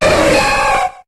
Cri de Suicune dans Pokémon HOME.